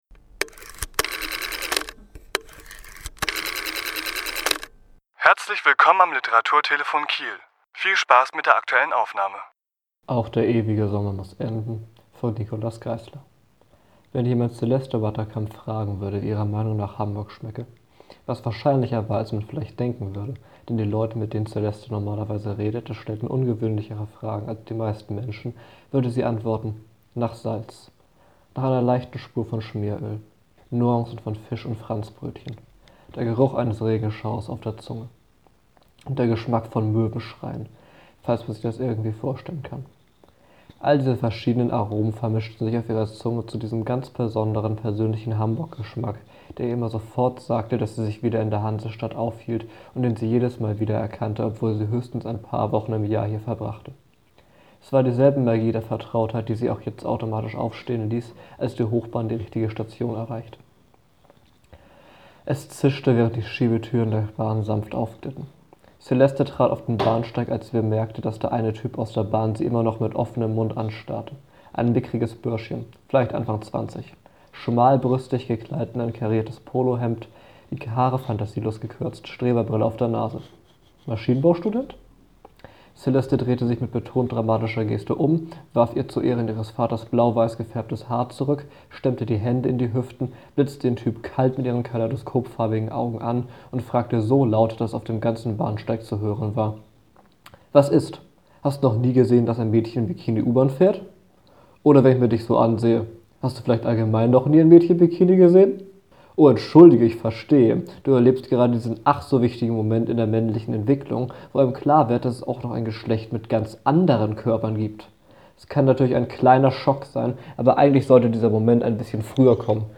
Autor*innen lesen aus ihren Werken
Wir präsentieren die Lesung des Autors in voller Länge (ca. 21 Minuten).